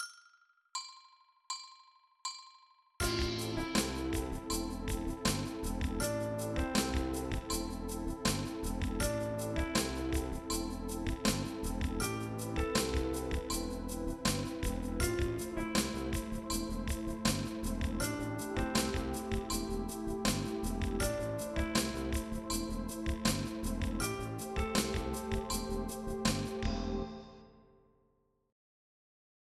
Spróbujcie dokładnie na raz atakować dźwięk spoza akordu w podkładzie. Następnie szybko i płynnie jakby zsuwamy się z niego na najbliższy dźwięk już z akordu: